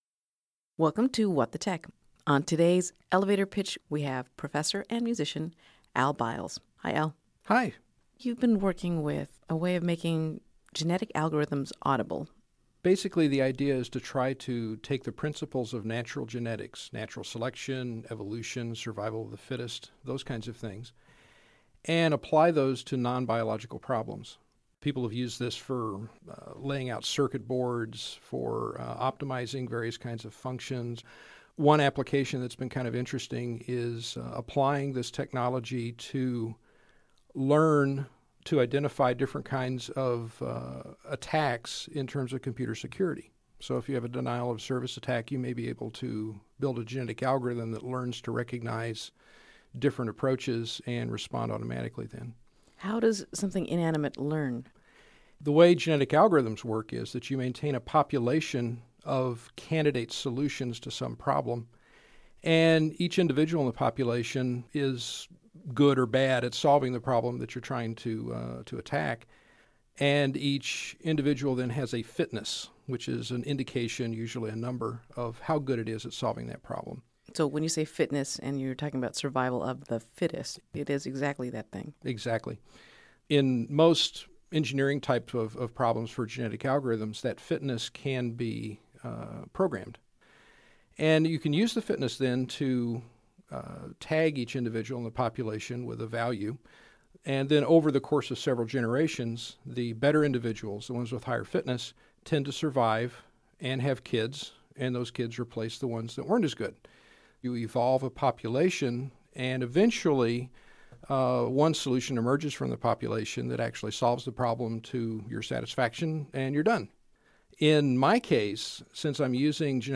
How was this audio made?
on WXXI radio